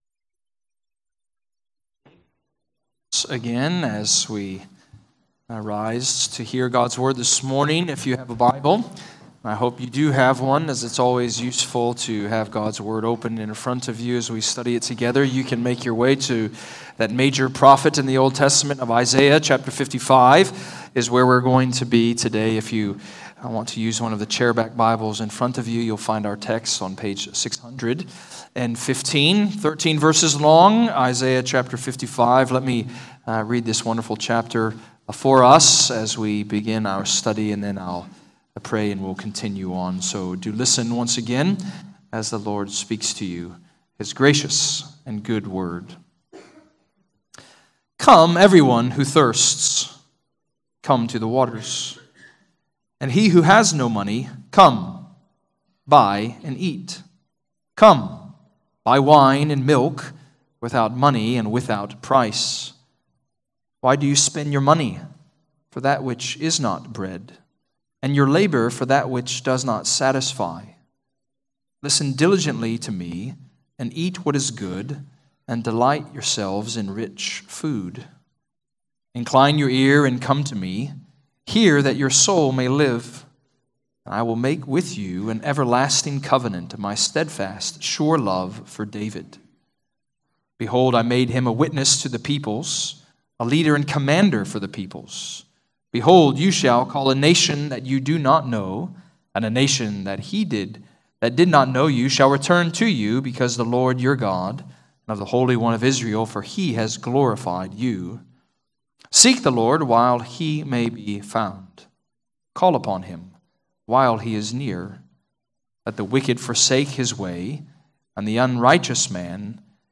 Redeemer Presbyterian Church: Sermon Audio
Download sermons from Redeemer Presbyterian Church in McKinney, TX.